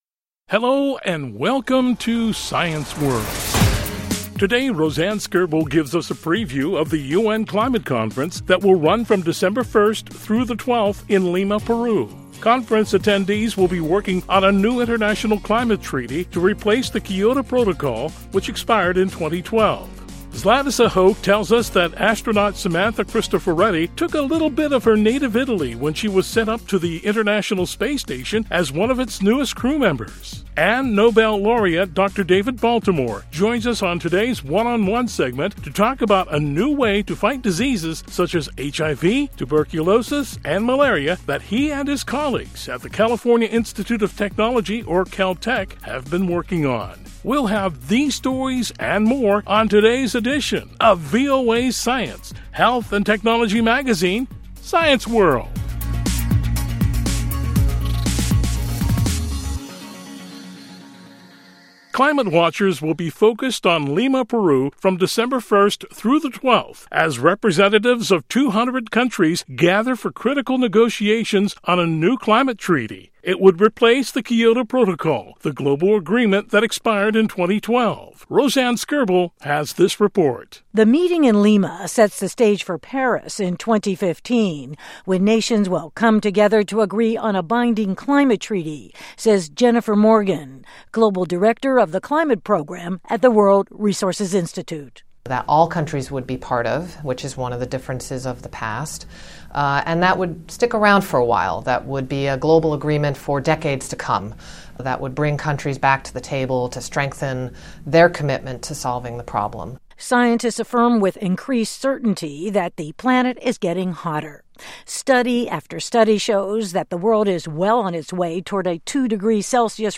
Nobel Laureate Dr. David Baltimore joins us on today’s One on One Segment to talk about a new way to fight diseases such as HIV, Tuberculosis and Malaria that he and his colleagues at the California Institute of Technology or Caltech have been working on.